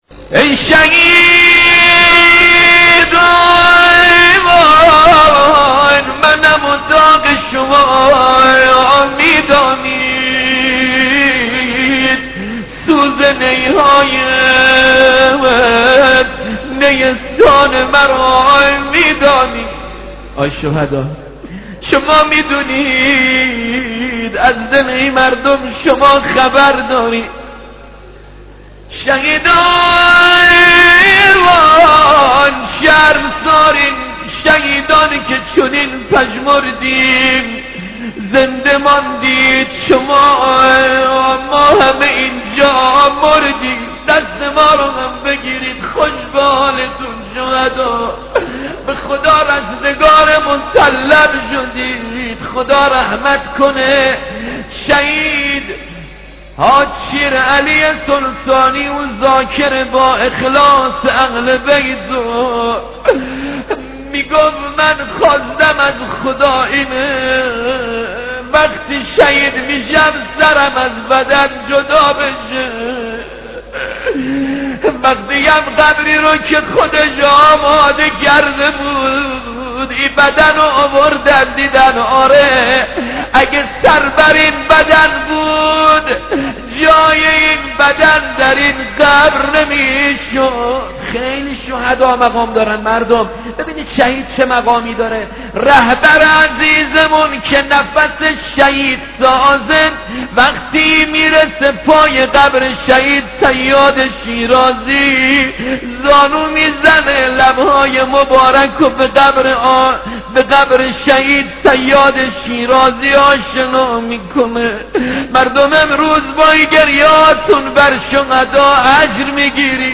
در مدح شهدا